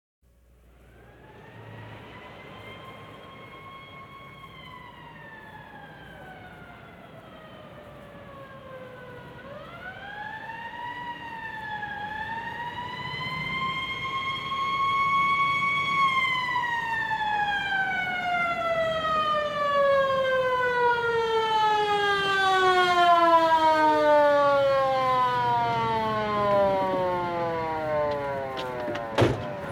siren.mp3